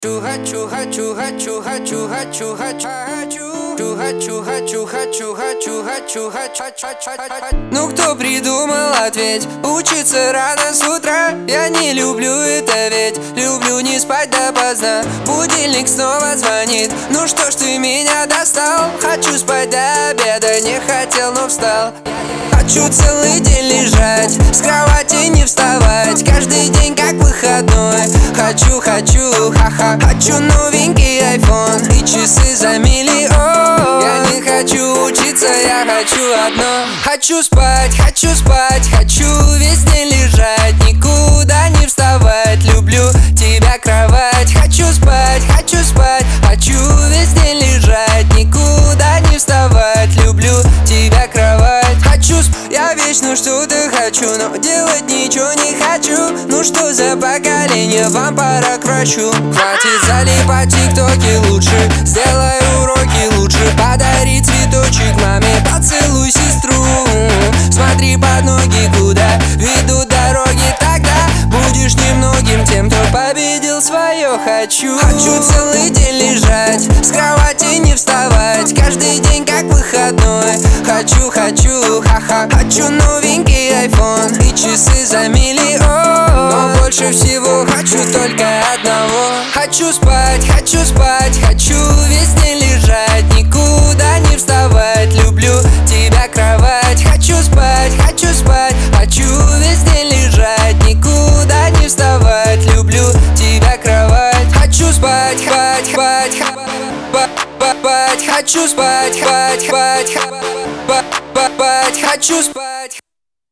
диско
Веселая музыка , pop